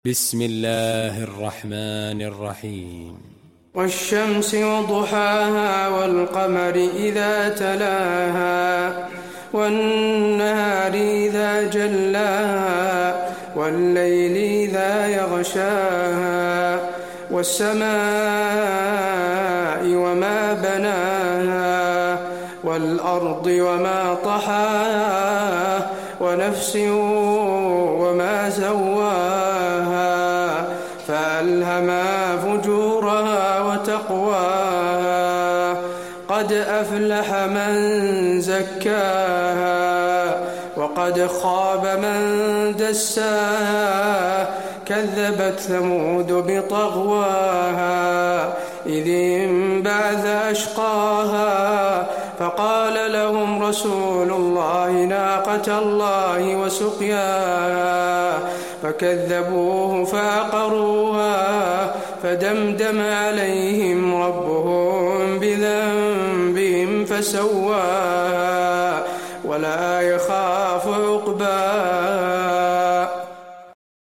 المكان: المسجد النبوي الشمس